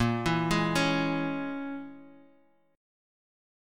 Bbm7b5 Chord
Listen to Bbm7b5 strummed